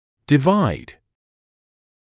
[dɪ’vaɪd][ди’вайд]делить, разделять; подразделять (на классы, категории и т.п.)